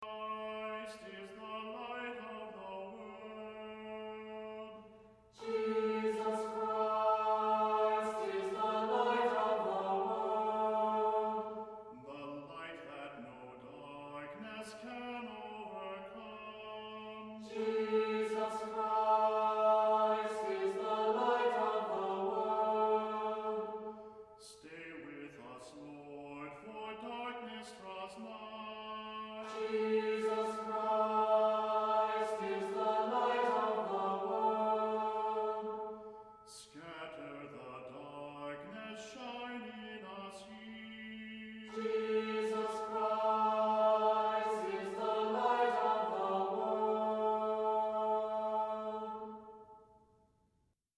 Voicing: Unison; Cantor; Priest